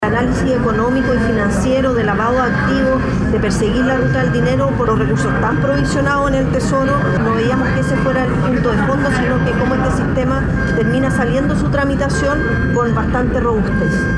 A pesar de la molestia oficialista, para el diputado de Amarillos, Andrés Jouannet, la tramitación va “en tiempo y forma”, resaltando que se visará el aumento de personal para la UAF.